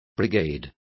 Complete with pronunciation of the translation of brigade.